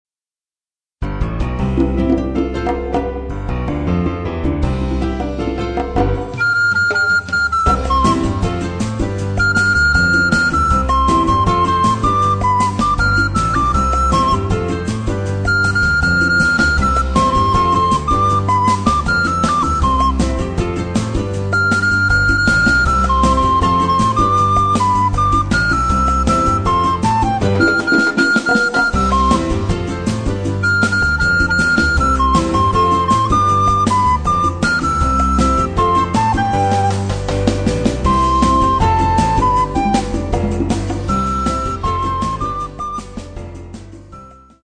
12 Vibrant themes with Latin flavour and spirit
Obsaazení: Sopran-Blockflöte